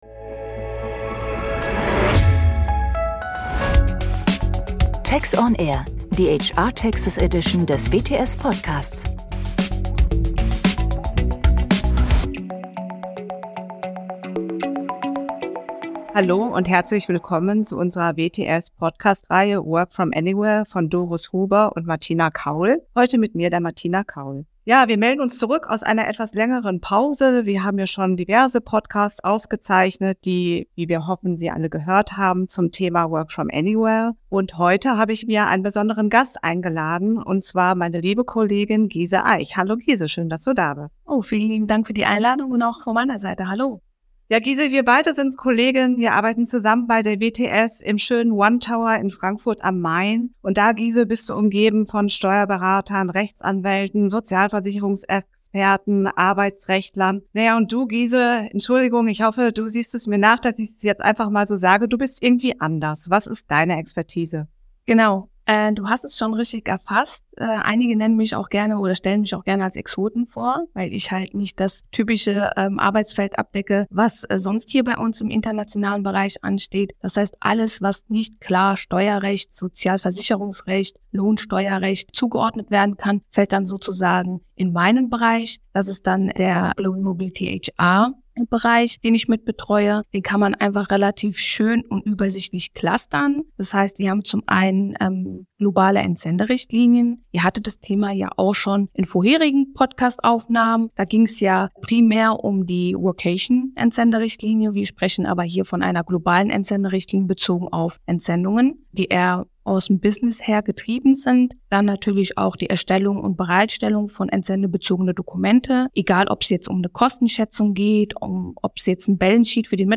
Die beiden erläutern diese und weitere Fragen im Gespräch und ordnen das Thema in den spannenden Kosmos von Work from Anywhere ein.